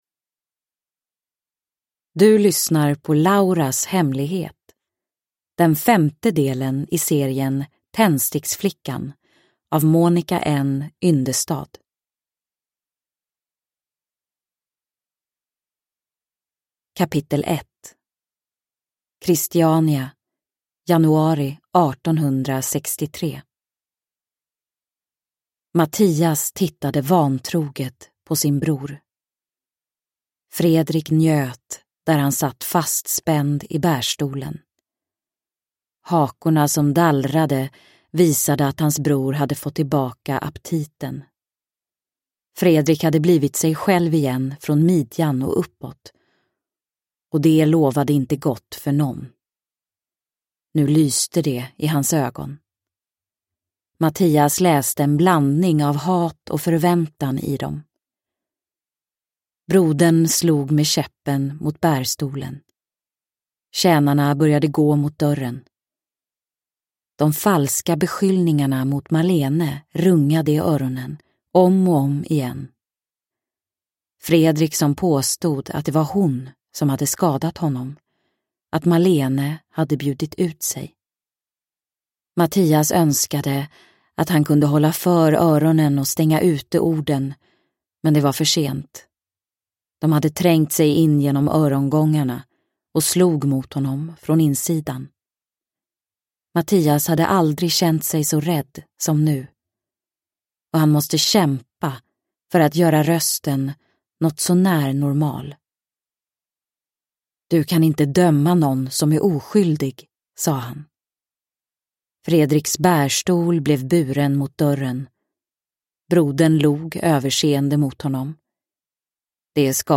Lauras hemlighet – Ljudbok – Laddas ner
Uppläsare: Tove Edfeldt